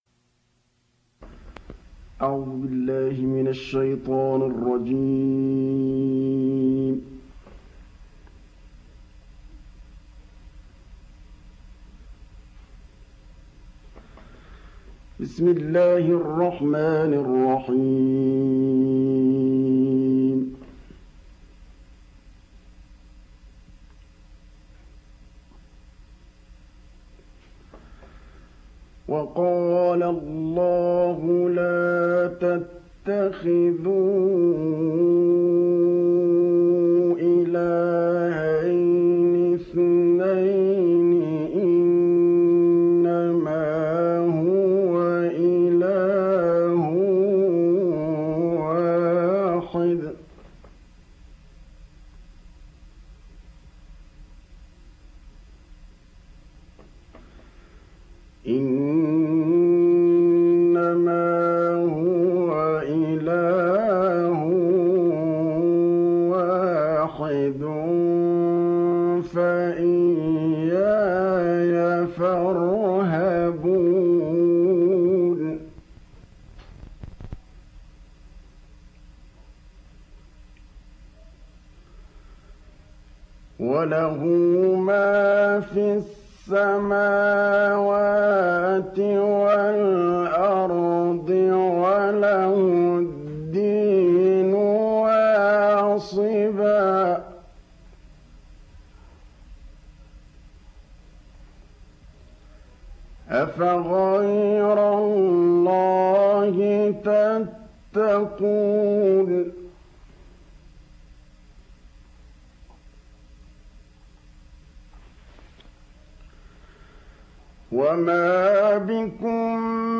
تلاوات مسجلة من الإذاعة السعودية للشيخ محمد محمود الطبلاوي